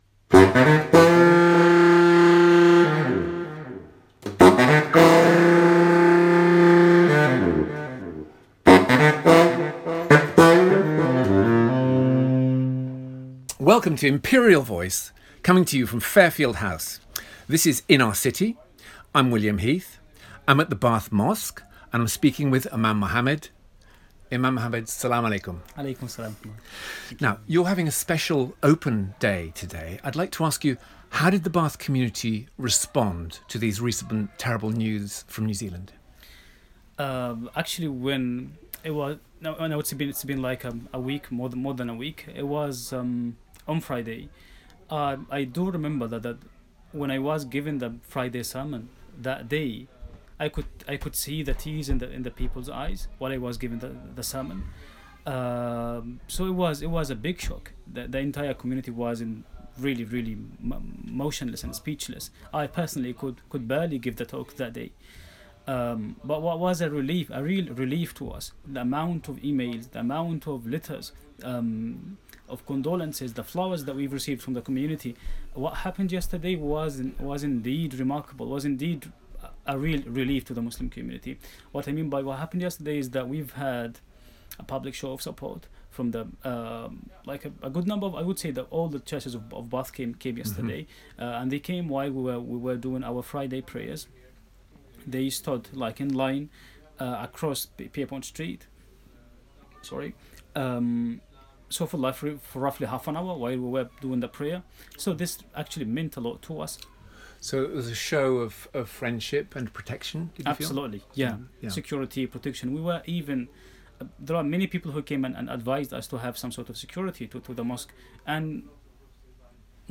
See below for exclusive new radio station preview.